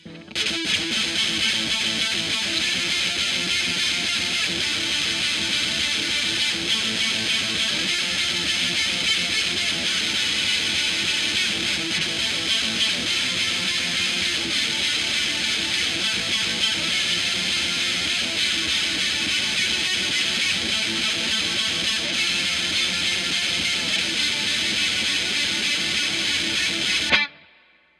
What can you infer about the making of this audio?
-Celestion Speaker 75 -SM57 Everything was recorded with the scarlet solo. Ultimate Chorus Dust Cap Edge.mp3